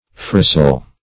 phrasal - definition of phrasal - synonyms, pronunciation, spelling from Free Dictionary